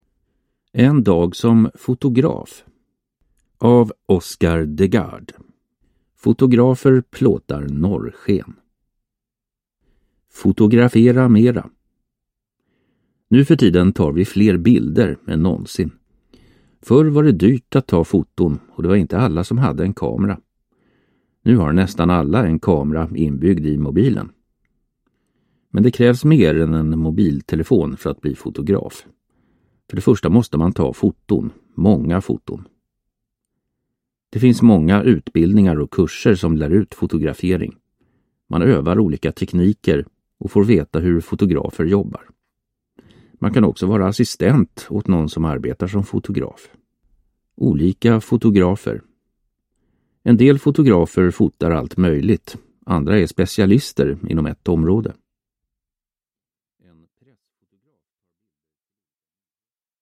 En dag som fotograf – Ljudbok – Laddas ner